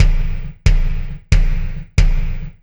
Kick Particle 09.wav